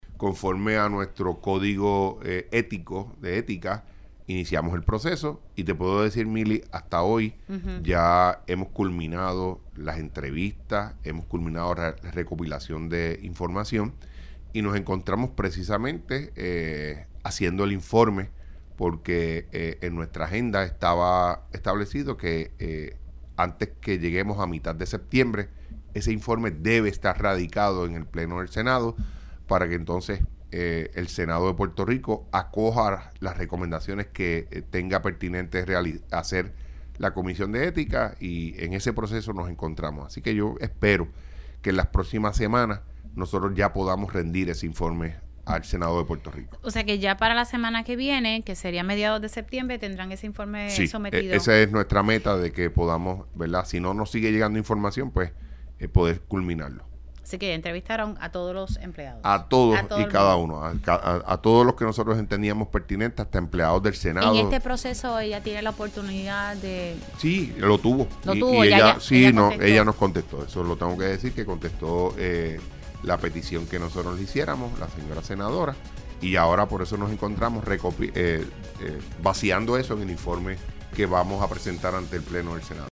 Así que yo espero que en las próximas semanas nosotros ya podamos rendir ese informe al Senado de Puerto Rico“, indicó el senador en Pega’os en la Mañana.